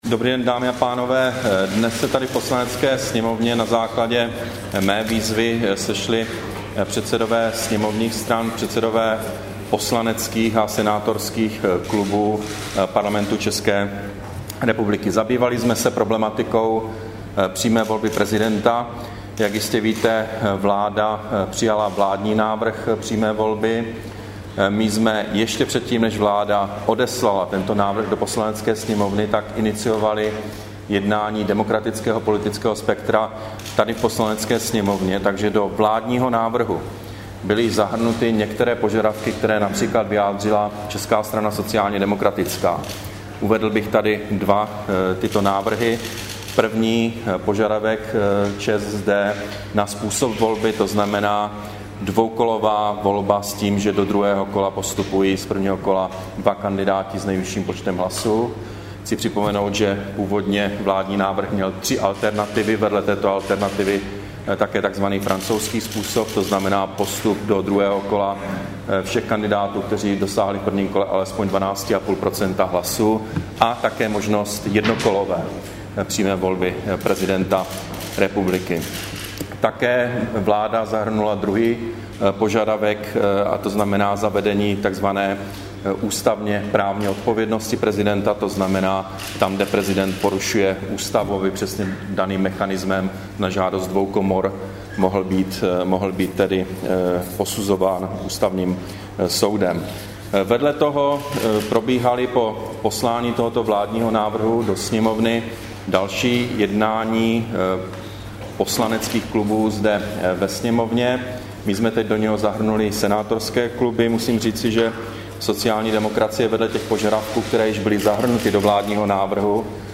Brífink premiéra po jednání zástupců parlamentních stran k přímé volbě prezidenta, 6. prosince 2011